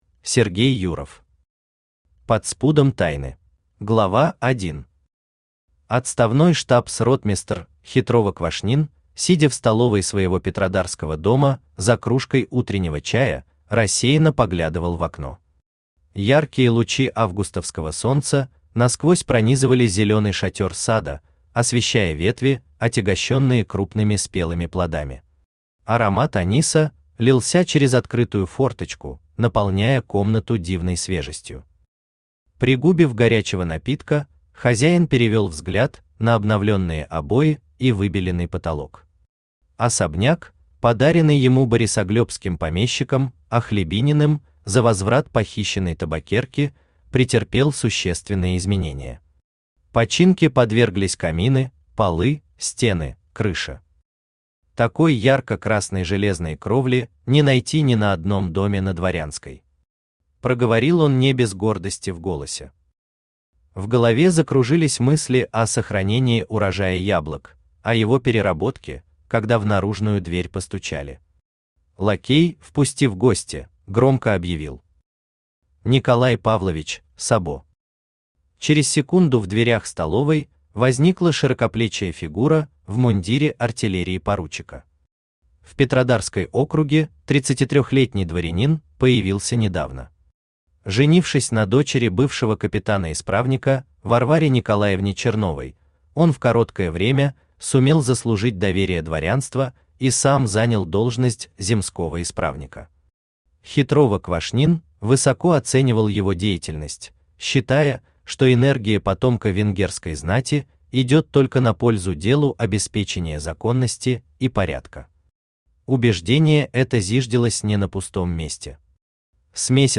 Читает: Авточтец ЛитРес
Аудиокнига «Под спудом тайны».